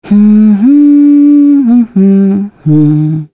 Scarlet-vox-Hum.wav